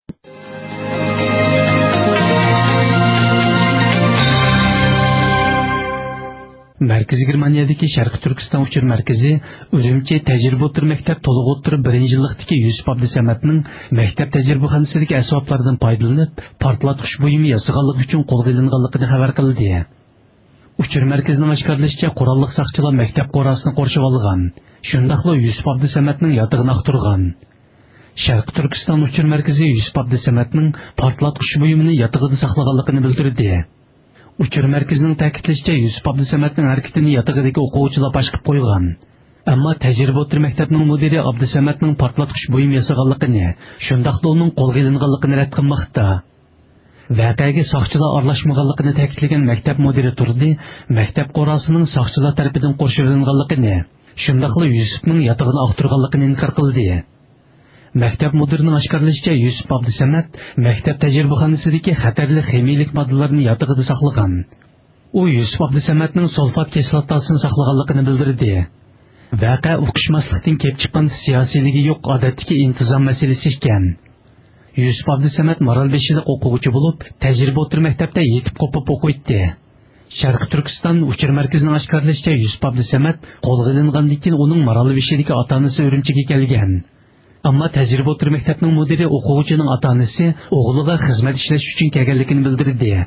Mexsus Radio S�hbeti